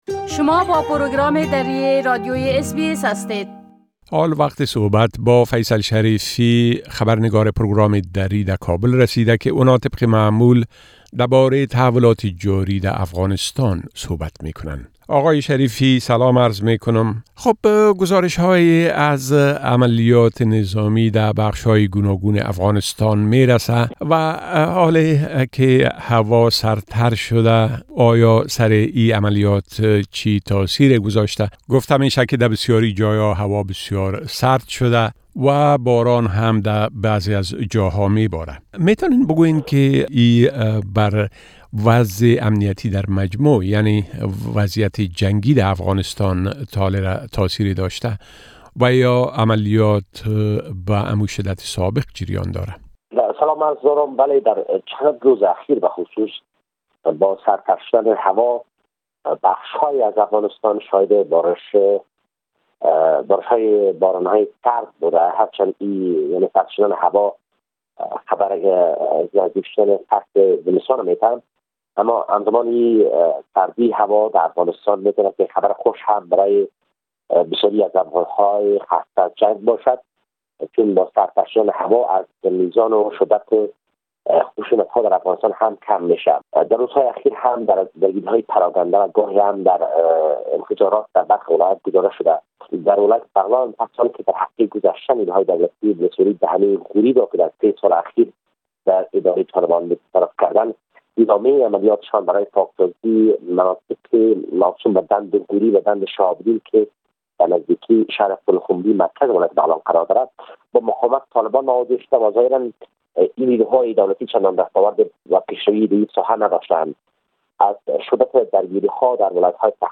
A report from our correspondent in Afghanistan which can be heard here in Dari language